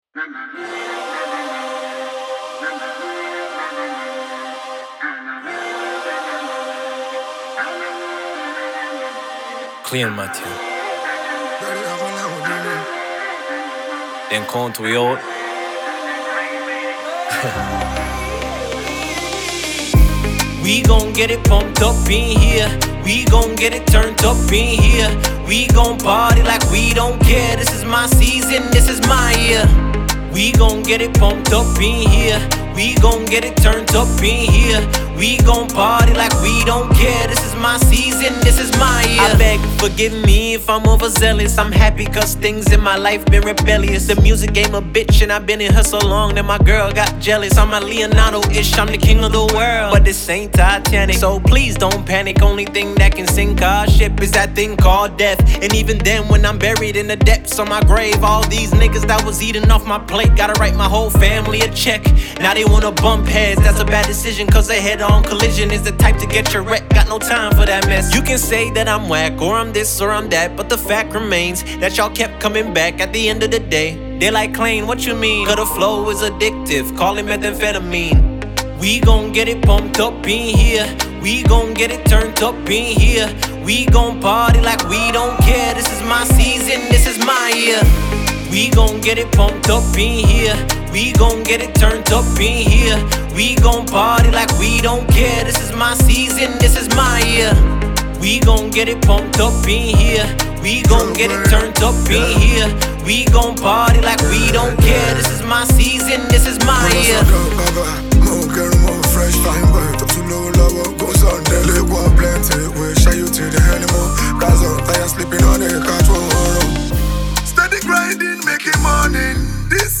Jamaican artiste and record producer
Nigerian Indigenous singer
Caribbean and African collaboration